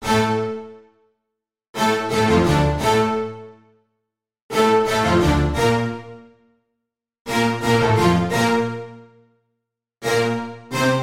管弦乐
描述：嘻哈圈
Tag: 87 bpm Hip Hop Loops Orchestral Loops 950.47 KB wav Key : D